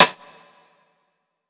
TC2 Snare 29.wav